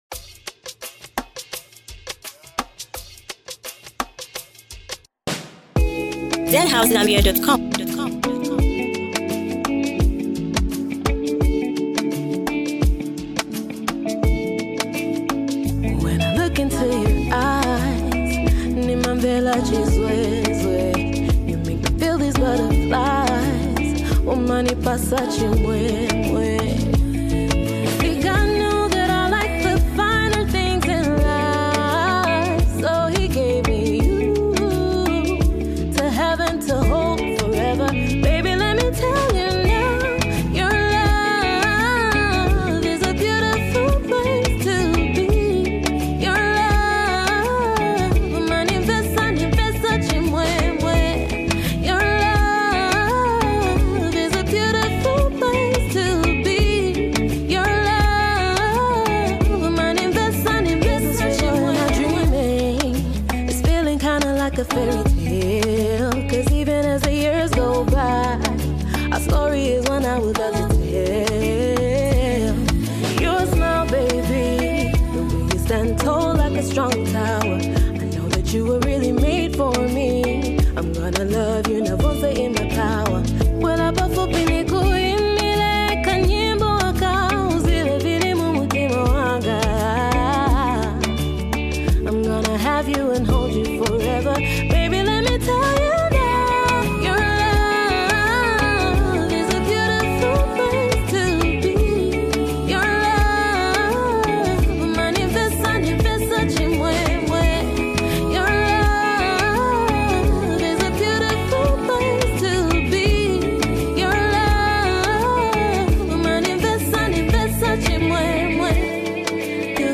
uplifting gospel song